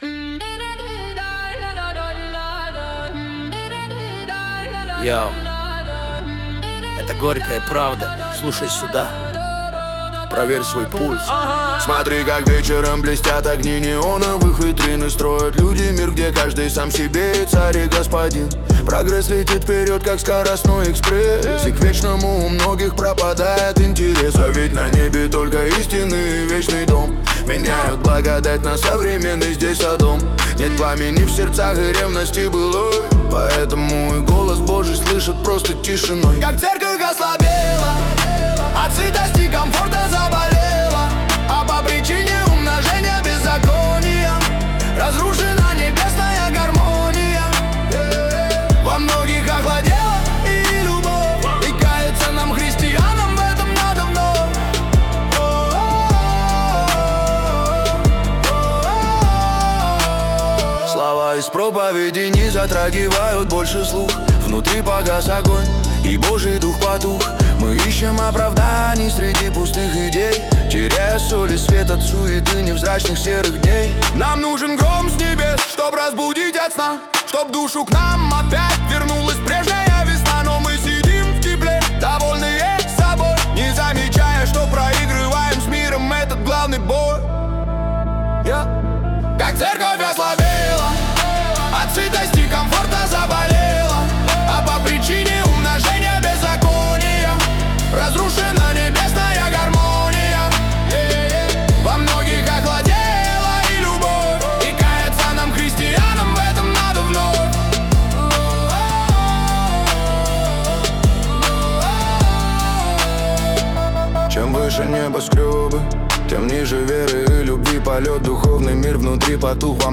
песня ai
141 просмотр 489 прослушиваний 51 скачиваний BPM: 78